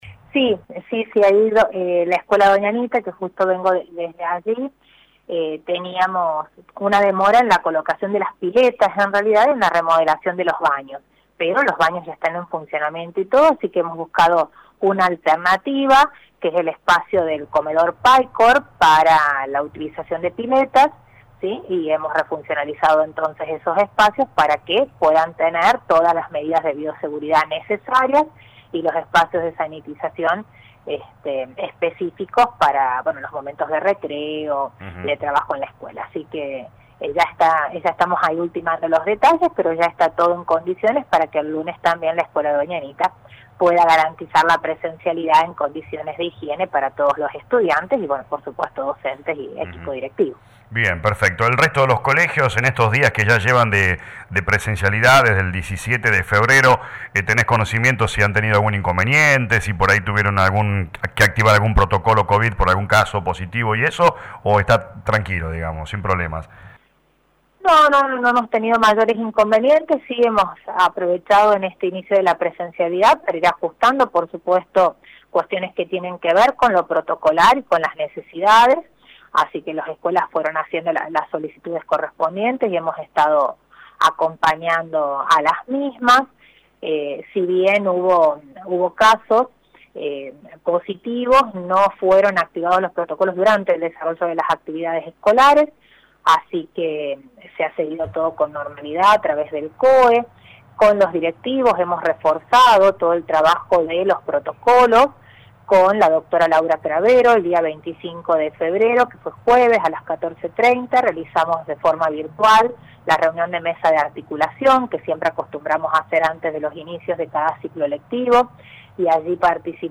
La Secretaria de Educación de Brinkmann Ing. Natalia Bosio habló en LA RADIO 102.9